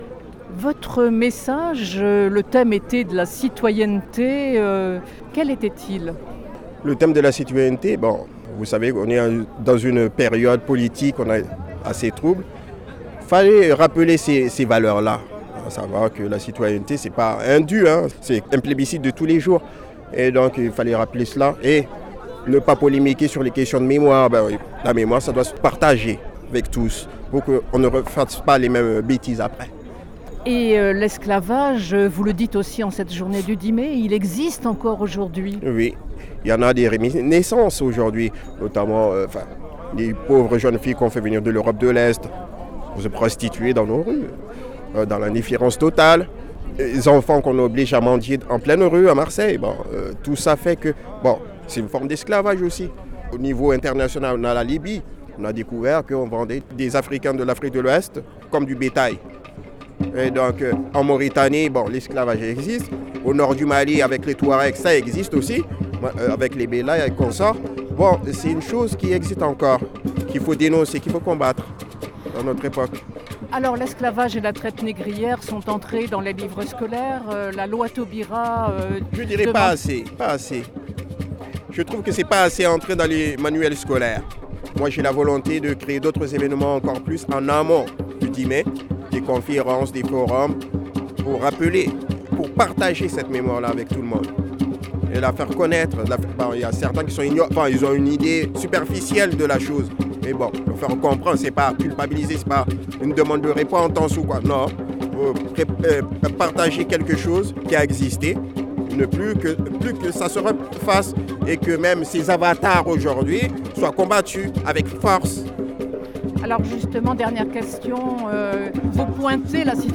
Marseille : Commémoration de l’abolition de l’esclavage sur le Vieux-Port